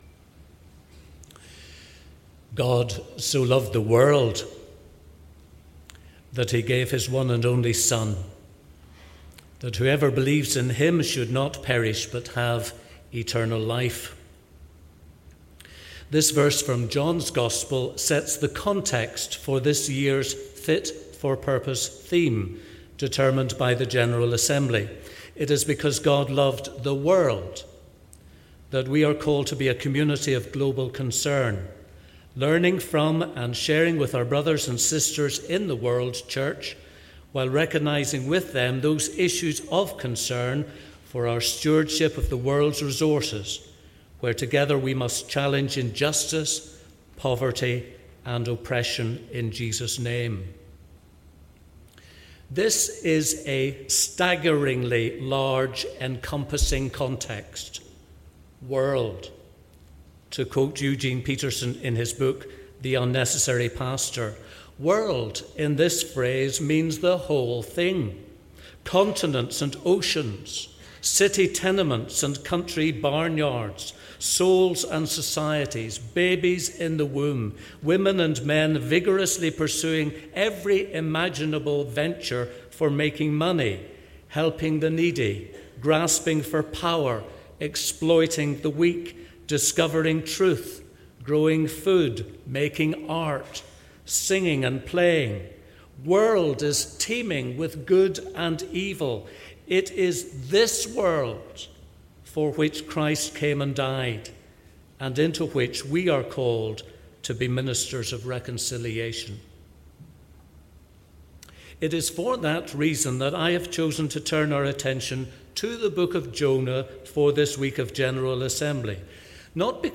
Address of the incoming Moderator, Dr Frank Sellar, to the Opening Meeting of the 2016 General Assembly.